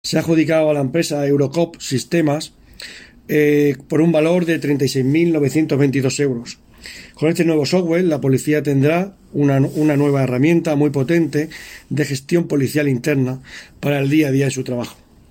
Declaraciones del concejal Antonio Expósito 1